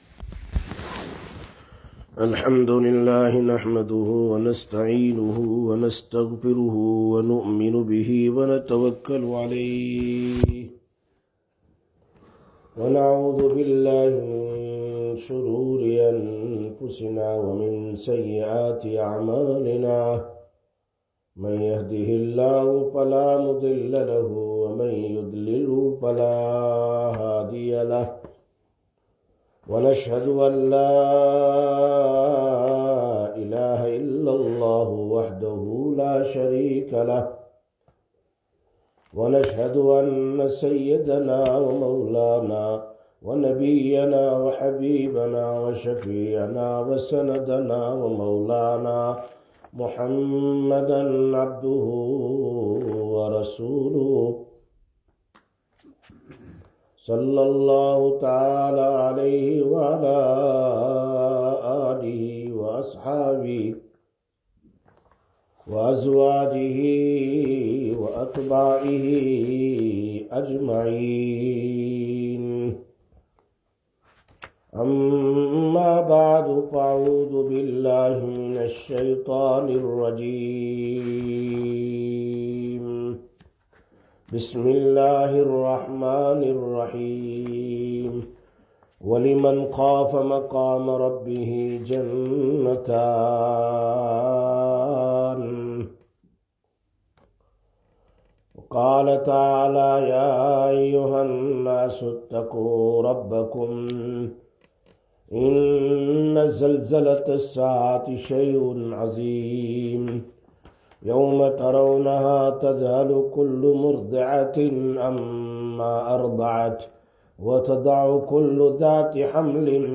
17/03/2021 Sisters Bayan, Masjid Quba